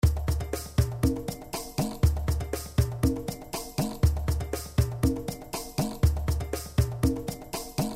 Congabeat MAS
描述：4条康加节拍的鼓声循环
Tag: 120 bpm Ethnic Loops Drum Loops 1.35 MB wav Key : Unknown